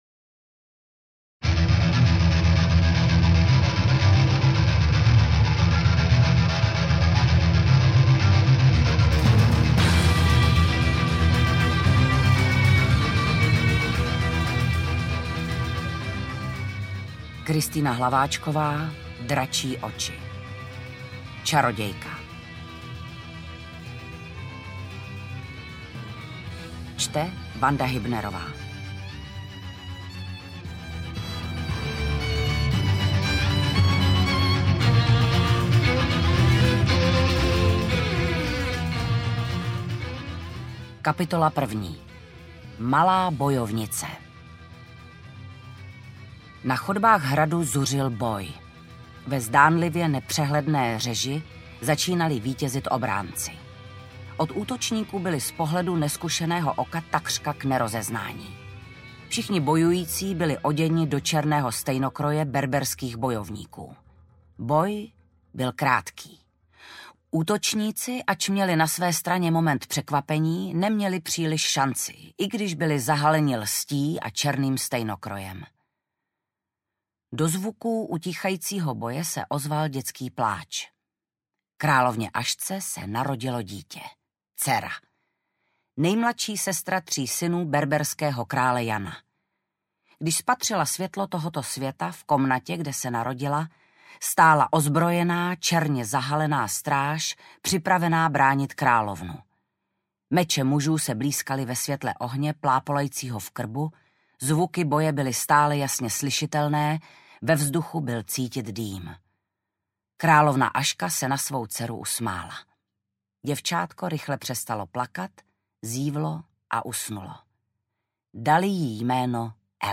• AudioKniha ke stažení Hlaváčková: Dračí oči - Čarodějka
Interpret:  Vanda Hybnerová
AudioKniha ke stažení, 37 x mp3, délka 13 hod. 38 min., velikost 747,4 MB, česky